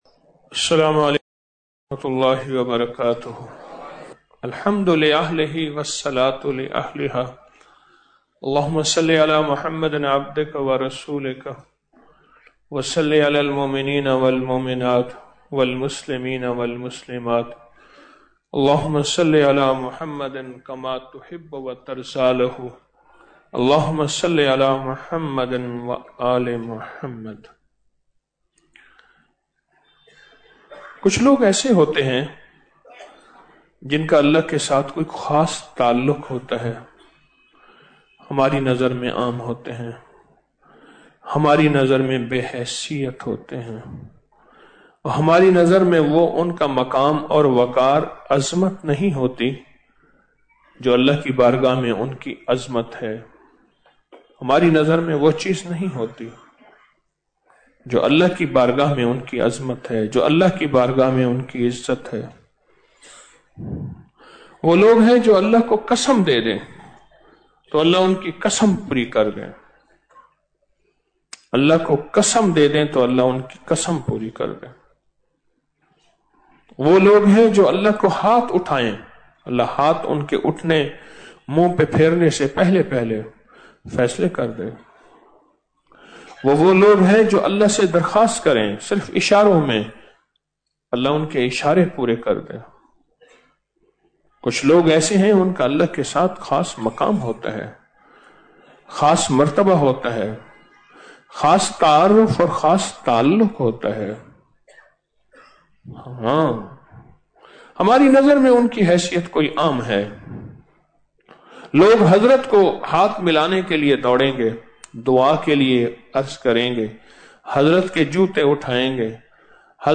Audio Speech - 13 Ramadan After Salat Ul Taraweeh- 13 March 2025